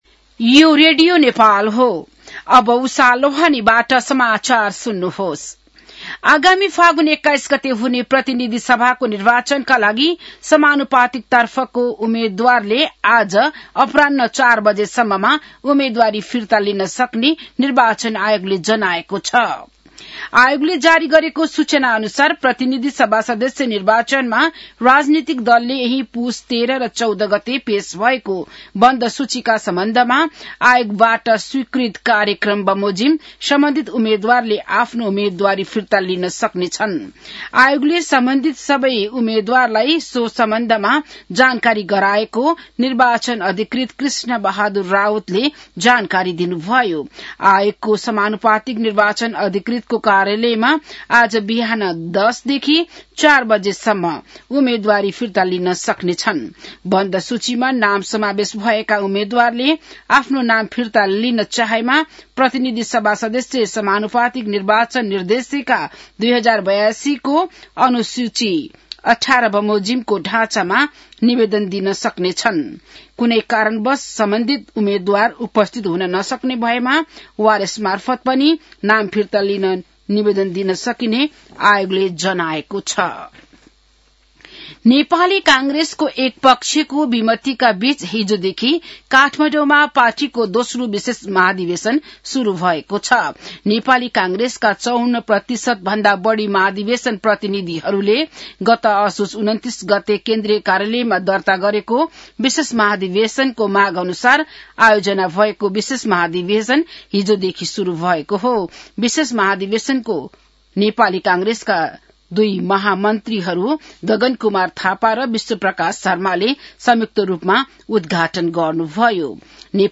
बिहान १० बजेको नेपाली समाचार : २८ पुष , २०८२